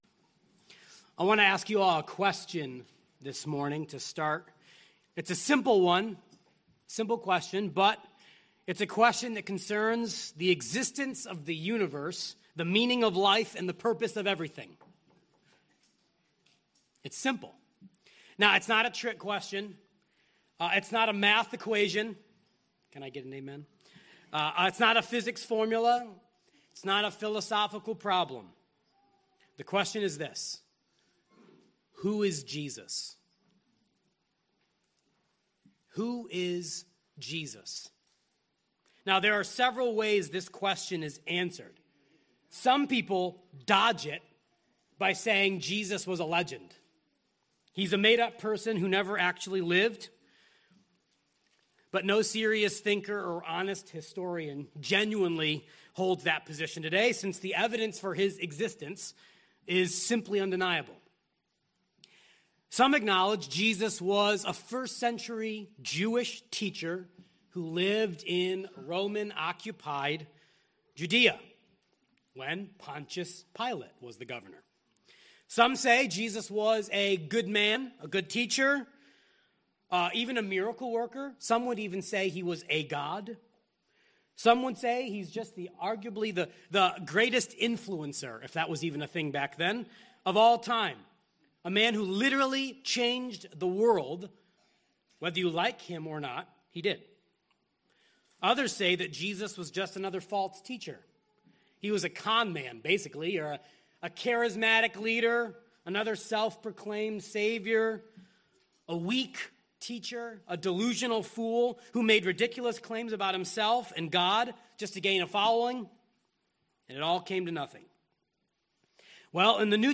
The God who speaks has made himself known in his Son, and in vv. 3-4 we learn he is the Son who sits. In this sermon